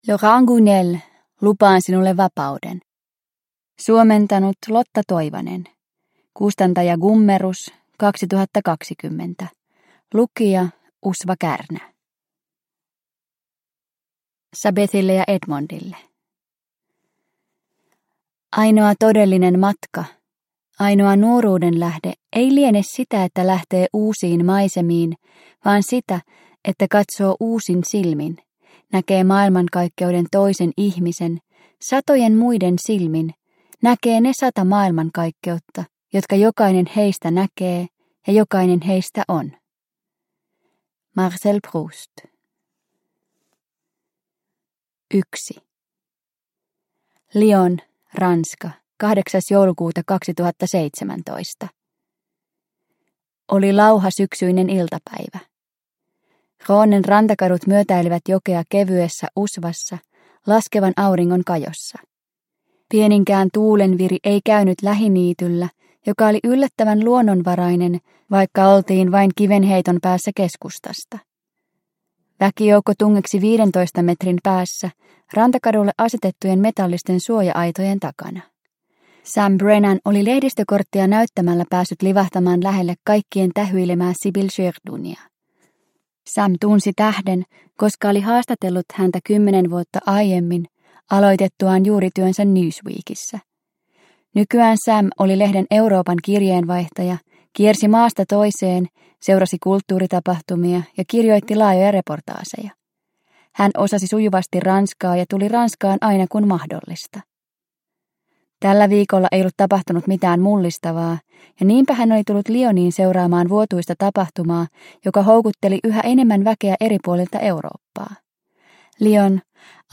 Lupaan sinulle vapauden – Ljudbok – Laddas ner